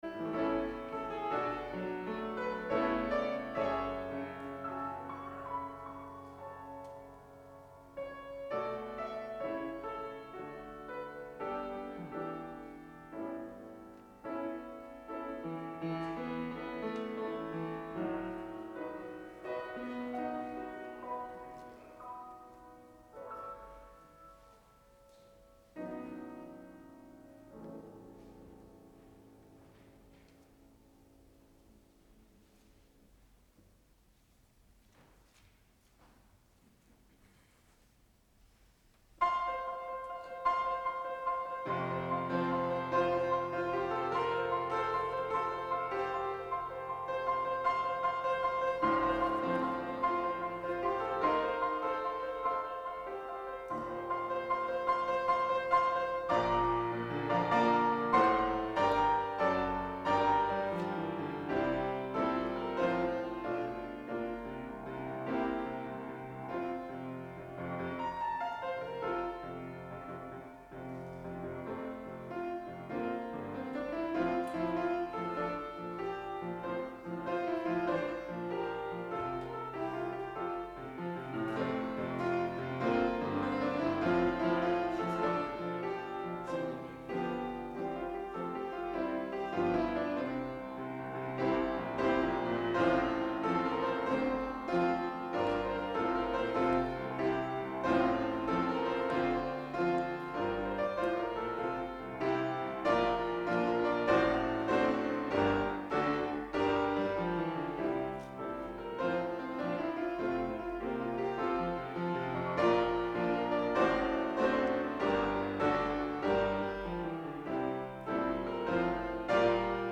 Audio worship files for CDPC 11.29.20 Service.